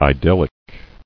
[i·dyl·lic]